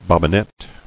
[bob·bi·net]